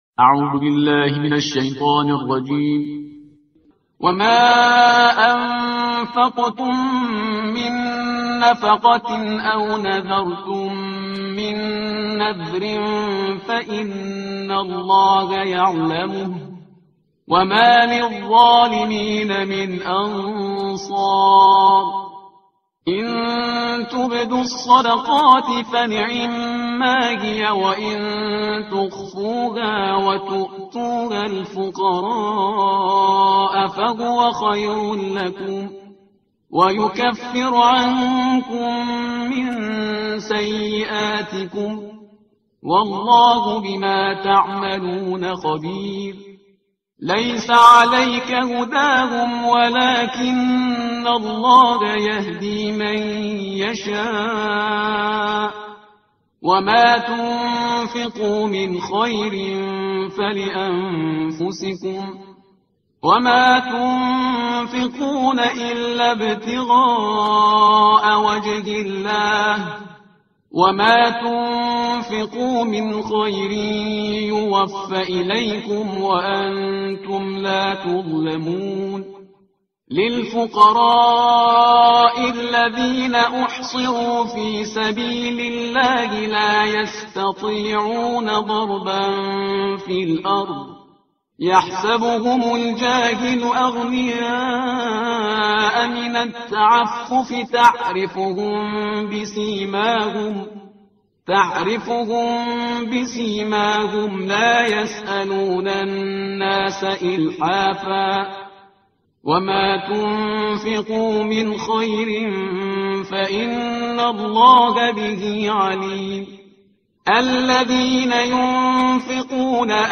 ترتیل صفحه 46 قرآن